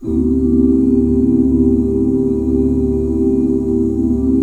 DSUS13 OOO-L.wav